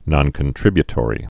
(nŏnkən-trĭbyə-tôrē)